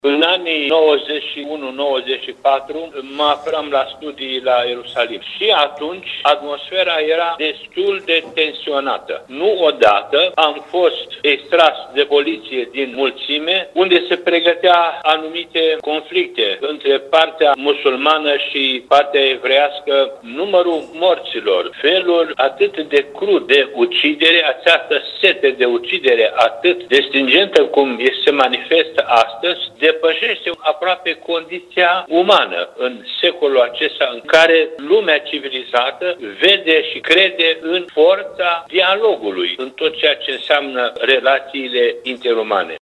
Înalt Prea Sfinția Sa, Ioan Selejan, mitropolitul Banatului care a studiat la Ierusalim la începutul anilor 90, a vorbit pentru Radio Timișoara despre conflictul fără precedent în ultimii 50 de ani izbucnit în Israel.
Interviul oferit de mitropolitul Banatului pe tema situației din Israel poate fi ascultat la Radio Timișoara în emisiunea Pulsul Zilei de miercuri, 11 octombrie, după ora 11.00.